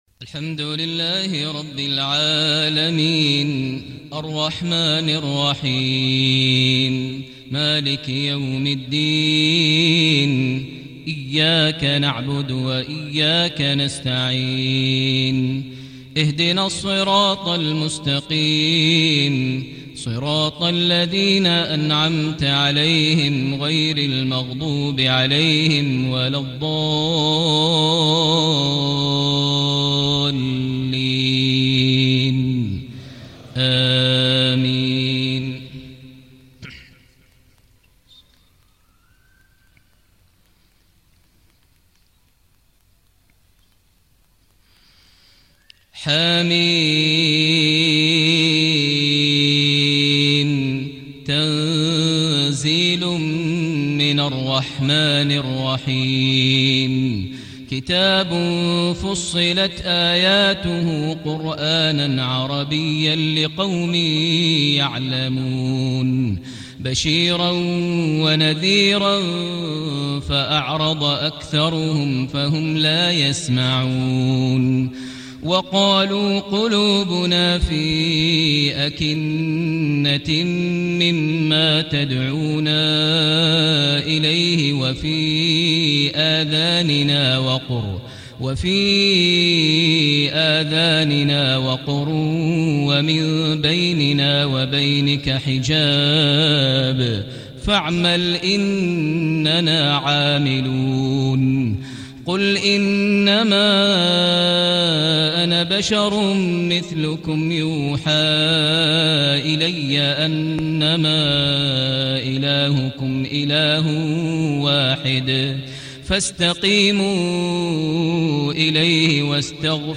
صلاة العشاء 3 - 2 - 1436 تلاوة من سورة فصلت > 1436 🕋 > الفروض - تلاوات الحرمين